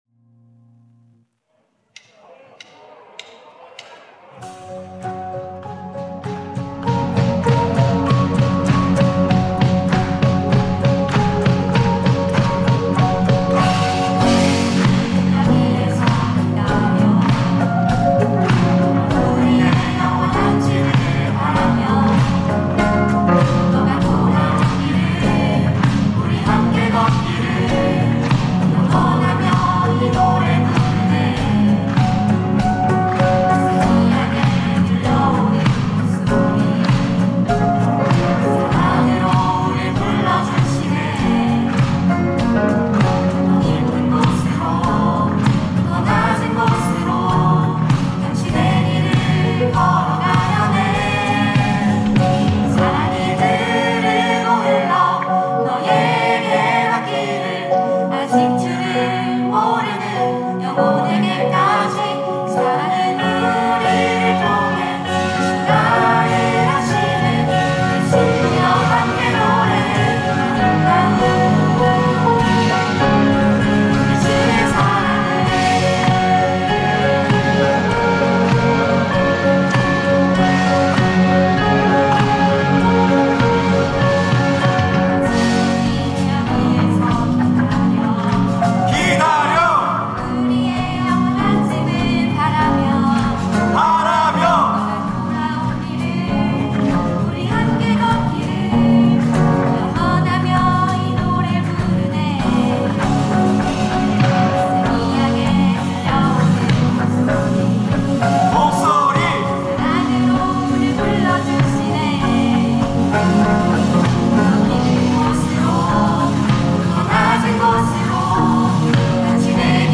특송과 특주 - 친구여 함께 노래해